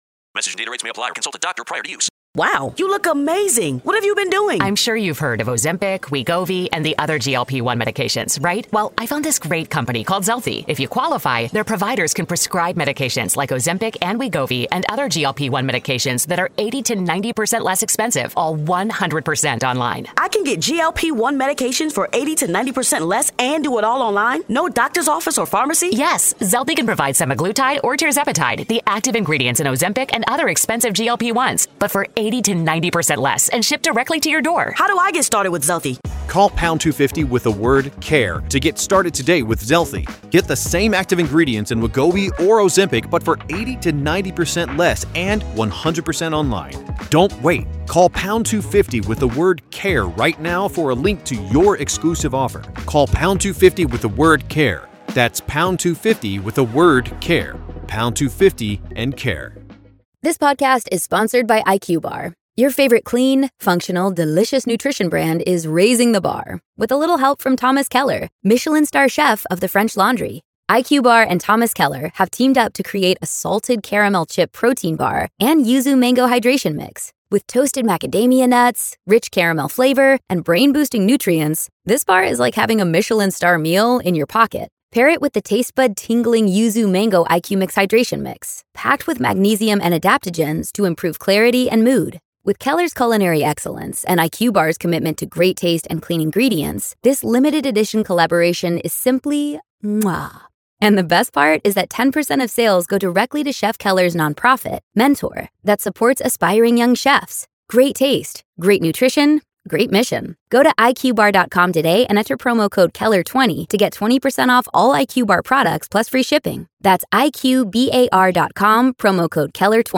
The story you've heard this week was narrated and produced with the permission of its respective author.